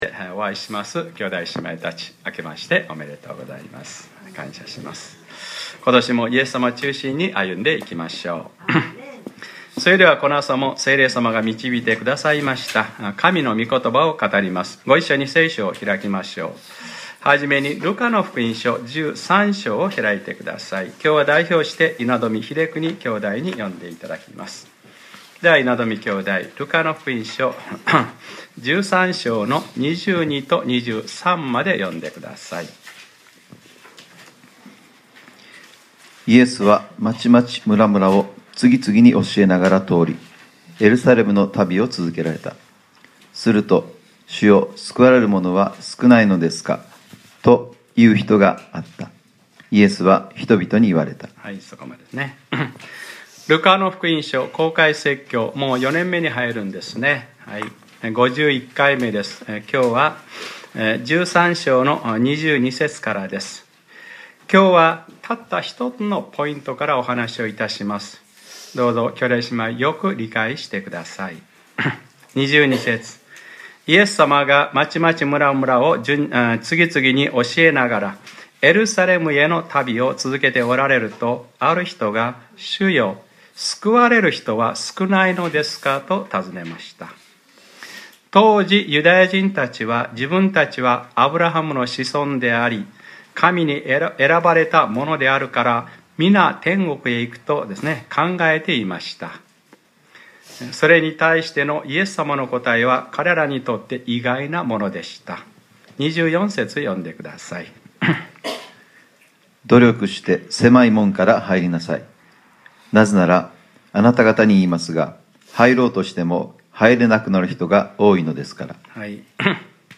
2015年01月04日）礼拝説教 『ルカｰ５１：努力して狭い門からはいりなさい』 | クライストチャーチ久留米教会